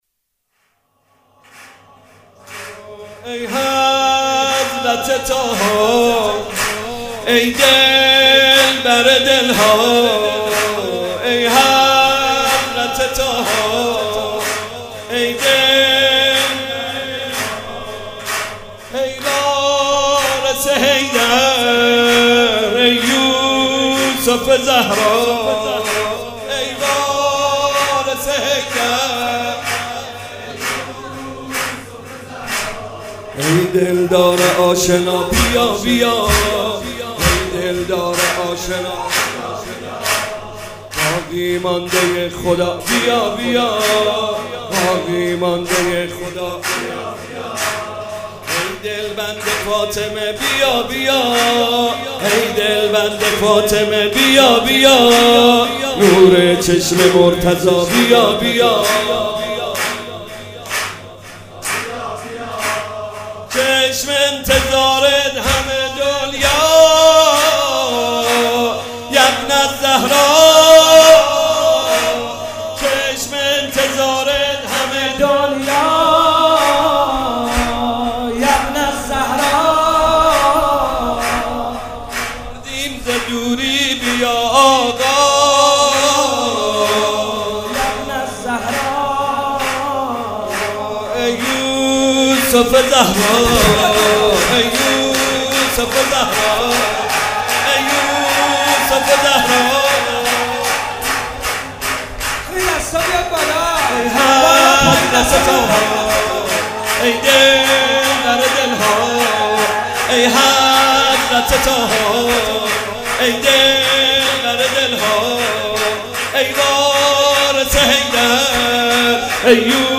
مدح امام حسن